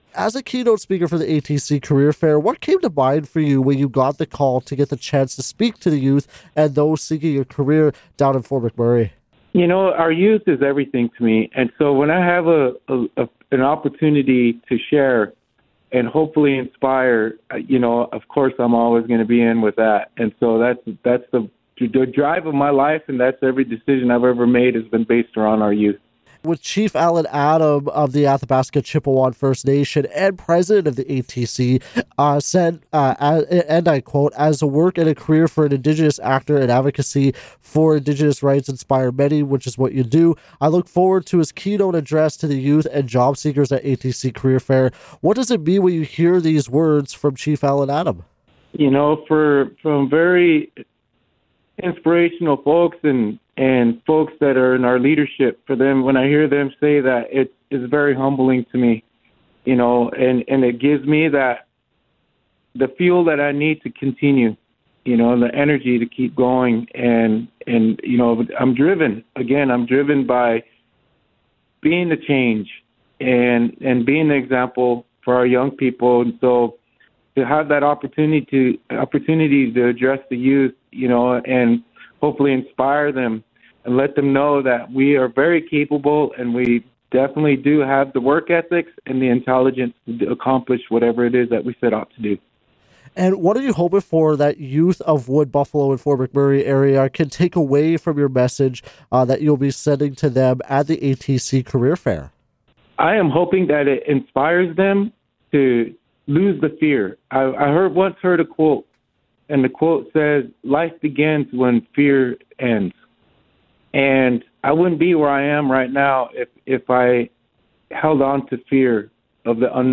Full interview with Mo Brings Plenty:
mo-brings-plenty-interview.mp3